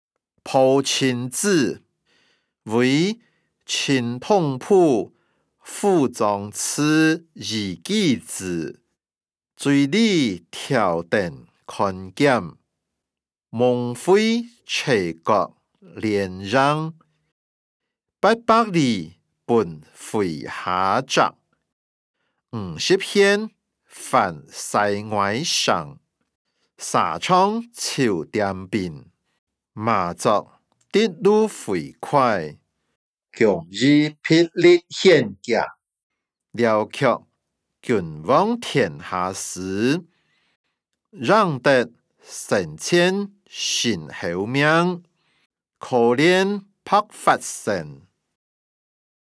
詞、曲-破陣子•為陳同甫賦壯詞以寄之音檔(饒平腔)